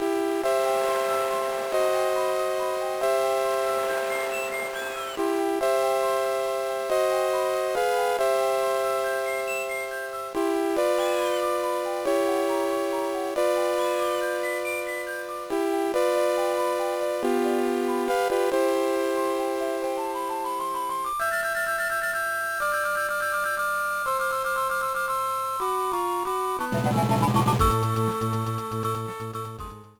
Trimmed and fadeout